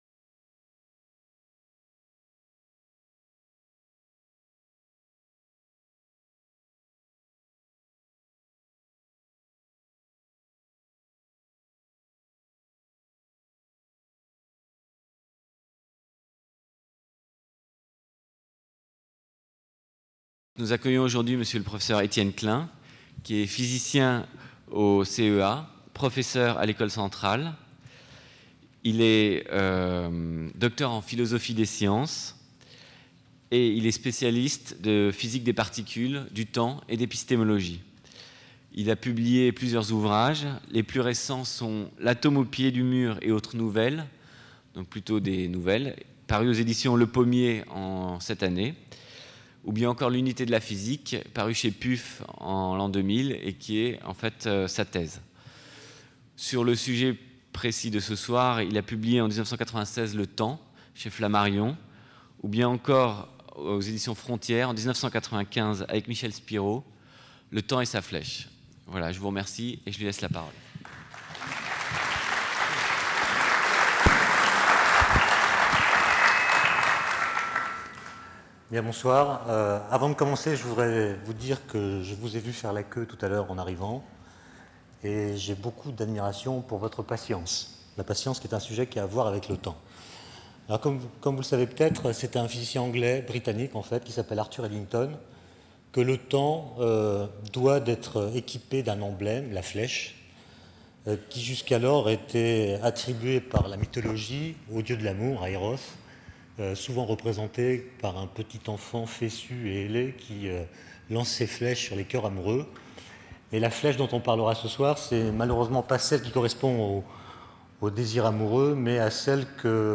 Conférence du 6 juillet 2000 par Etienne Klein.